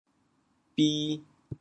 畀 部首拼音 部首 田 总笔划 8 部外笔划 3 普通话 bì 潮州发音 潮州 bi2 文 潮阳 bi2 文 澄海 bi2 文 揭阳 bi2 文 饶平 bi2 文 汕头 bi2 文 中文解释 潮州 bi2 文 对应普通话: bì ①赐与。